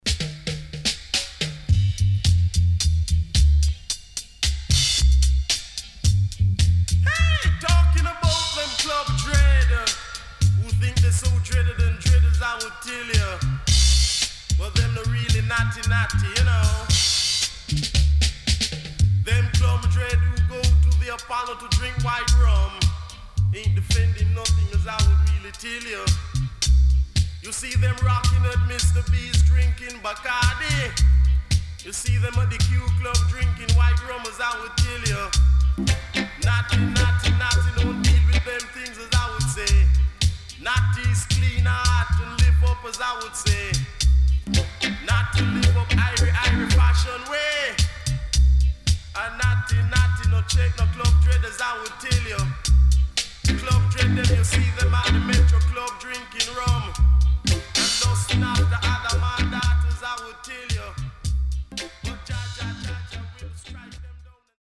Sweet Soulful Ballad & Deejay.W-Side Good
SIDE A:少しチリノイズ入りますが良好です。